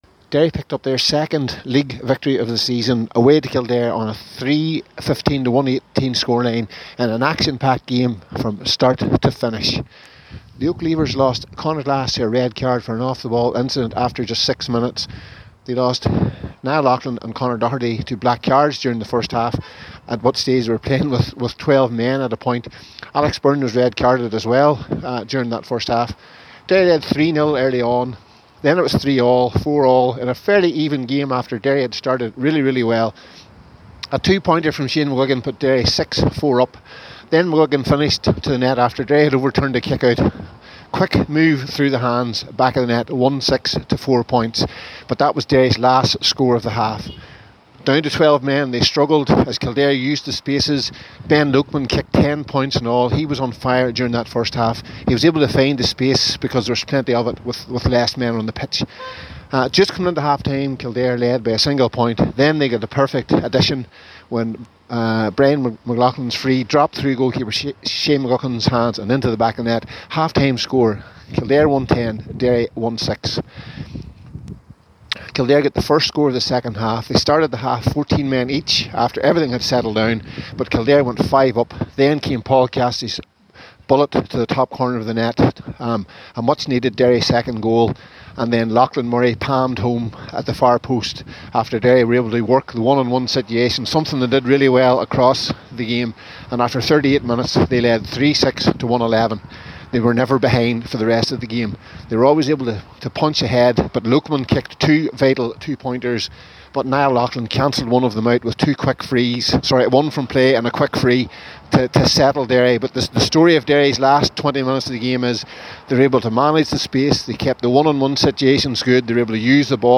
reports from Newbridge: